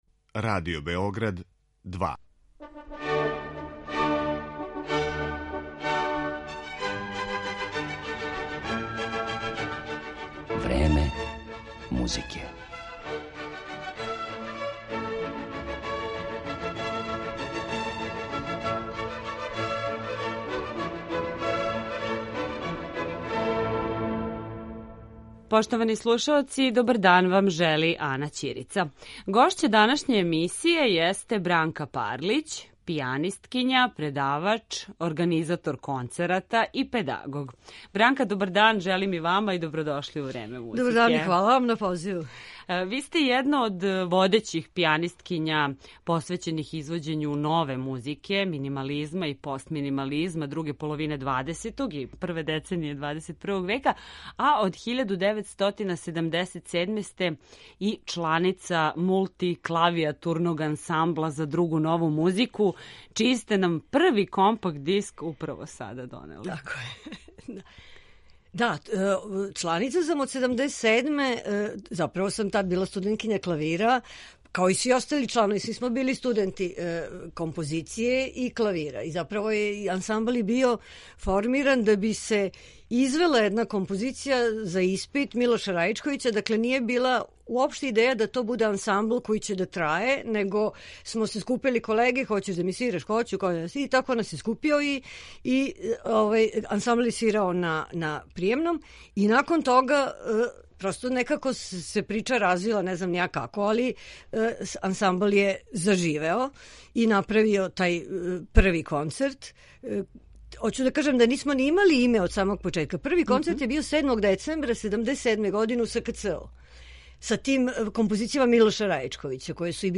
Гошћа Времена музике